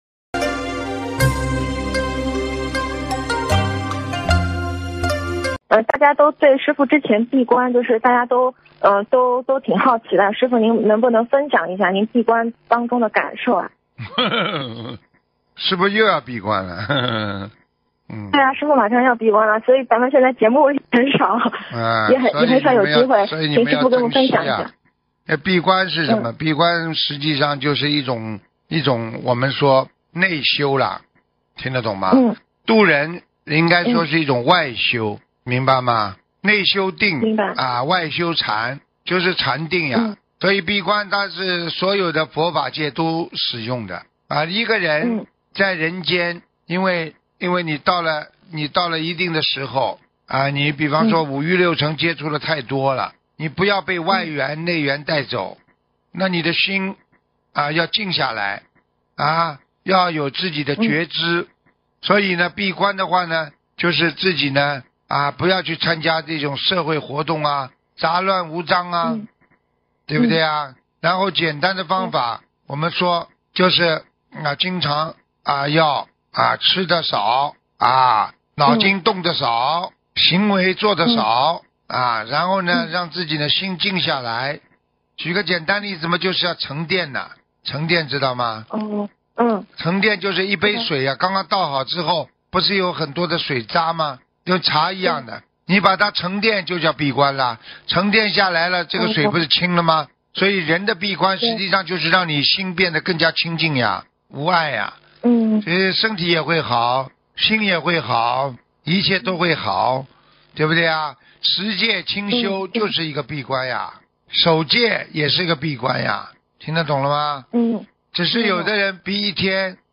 音频：师父开示闭关的意义!问答2019年06月21日43分27秒!